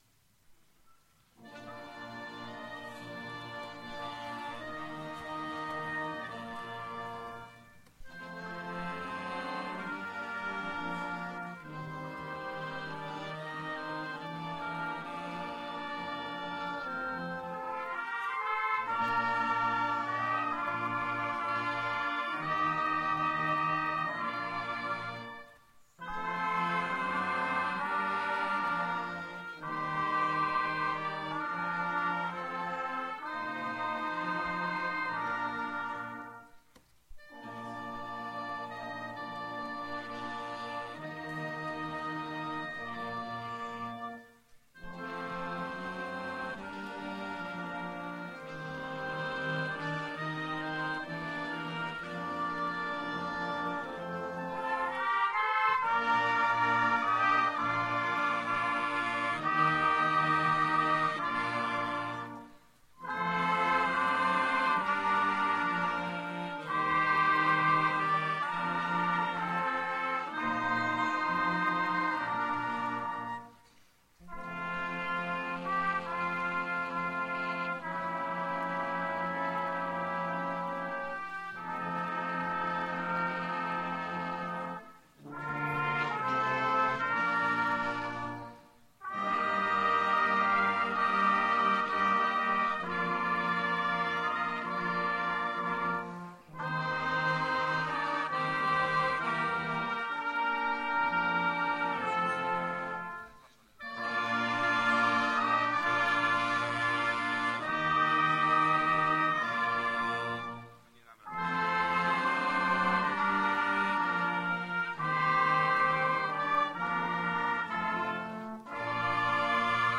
Jak co roku główne uroczystości ku czci św. Kingi w Starym Sączu poprzedzone są nowenną.